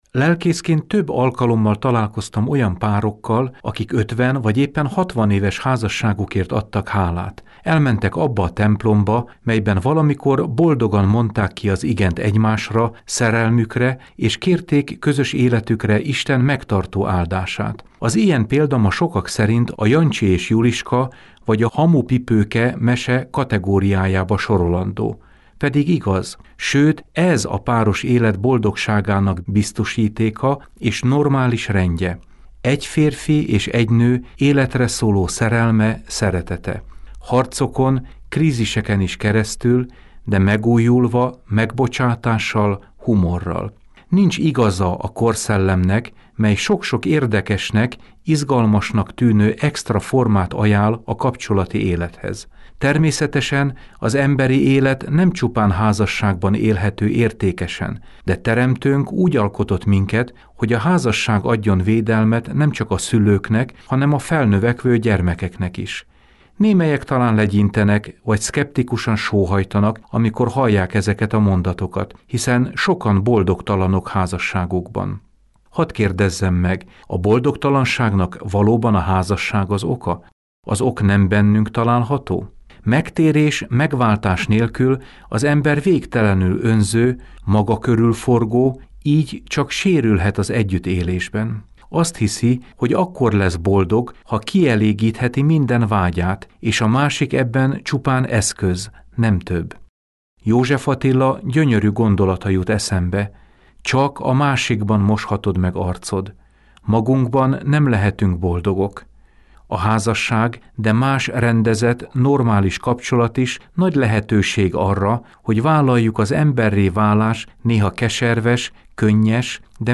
Napi útmutatói áhítat